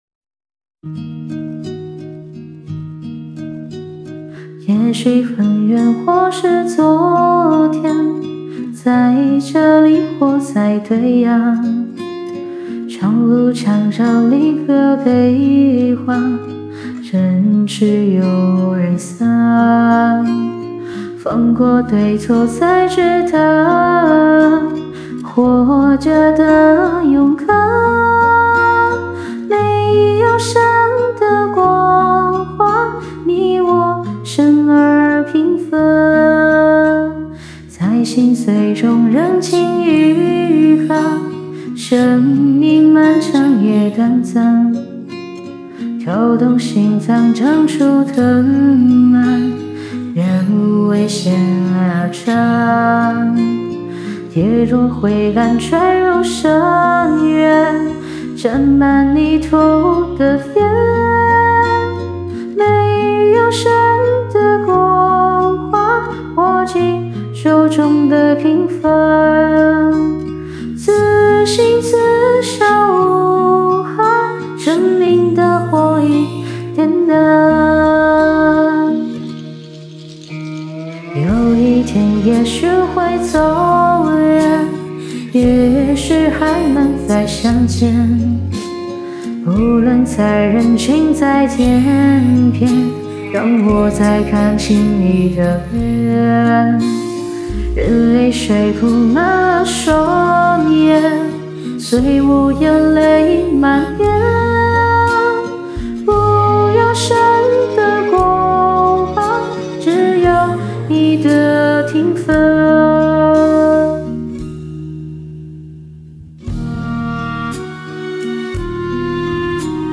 低沉的钢琴前奏响起，富有层次的声音如细雨般浸润耳畔，生命最本真的模样缓缓展现眼前——生命无需神的光环，平凡本身，就是一种伟大，是无数个普通瞬间交织而成的星河璀璨。